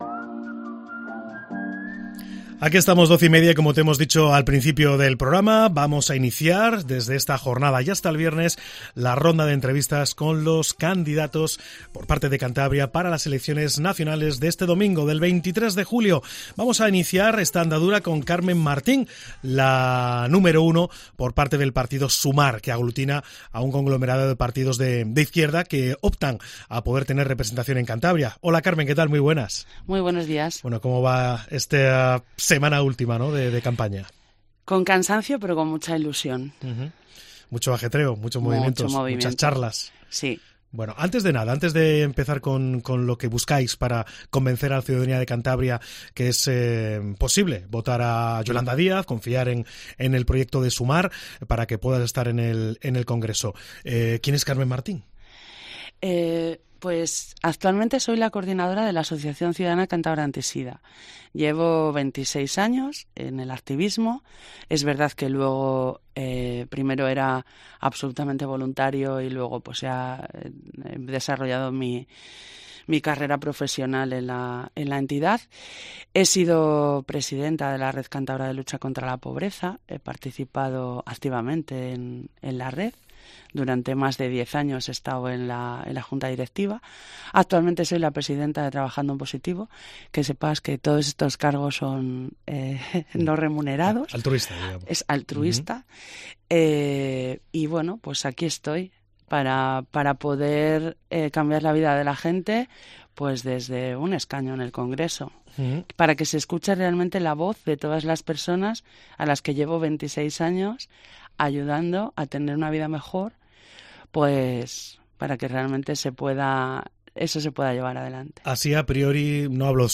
en una charla en Cope Cantabria